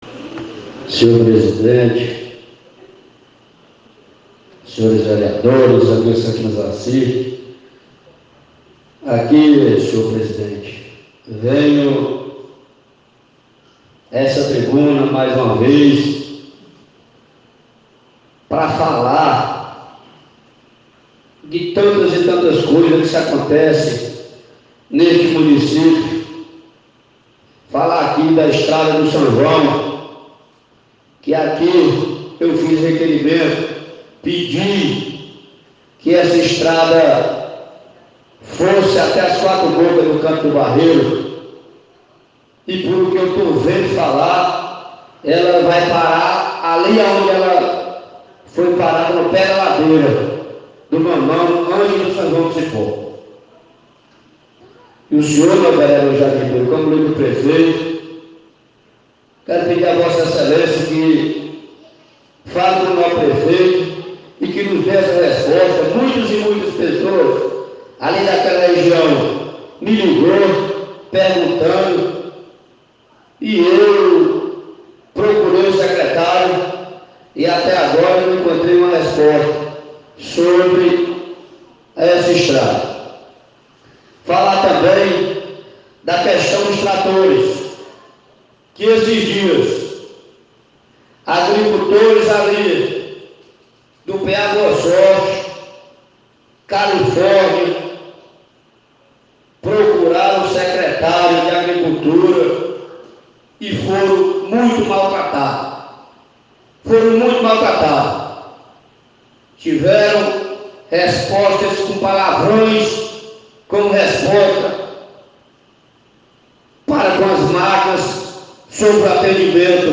Na sessão ordinária desta segunda-feira, 19, o vereador Miguel do Cajueiro (MDB), criticou duramente parte dos secretários municipais da gestão Cláudio Santana (MDB). Cajueiro disse que falta planejamento e respeito por parte dos gestores das pastas.